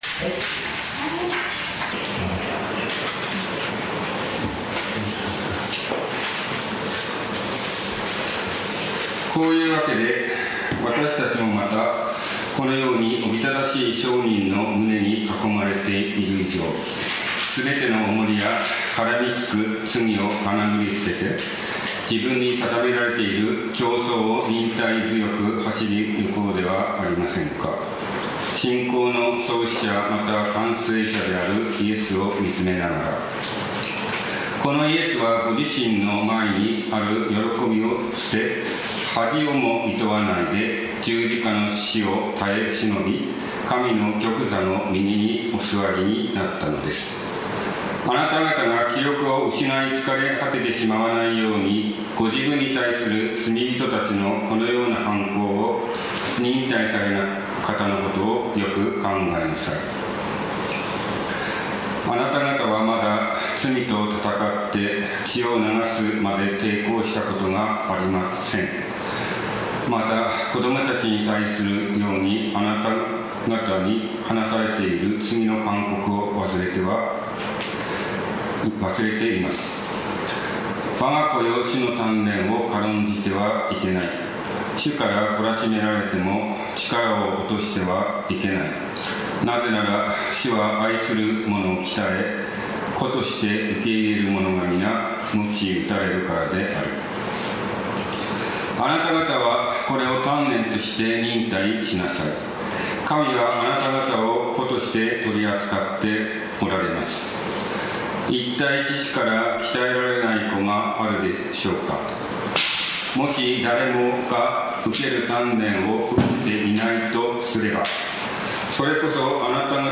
礼拝説教アーカイブ 日曜 朝の礼拝